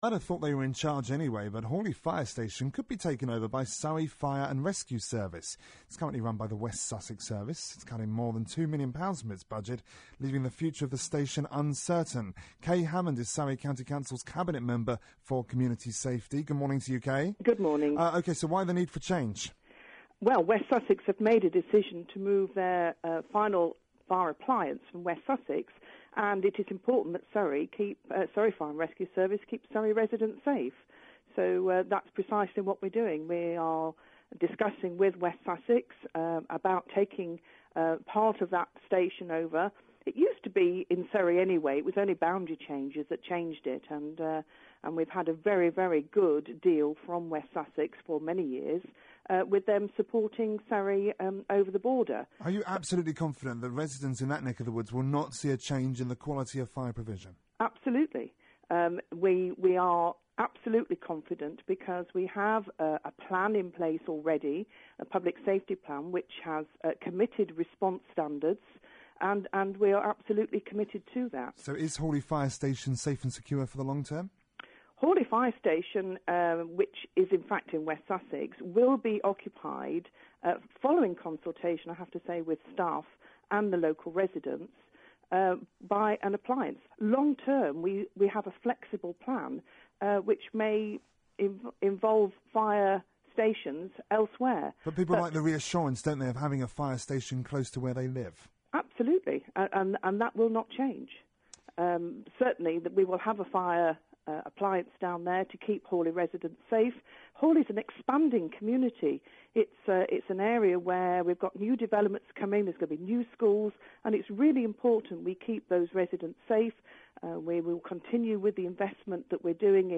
Cabinet Member for Community Safety Kay Hammond was interviewed on BBC Surrey about plans to continue providing fire cover to Horley residents when West Sussex Fire and Rescue Service remove their fire engine.